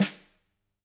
snare1.mp3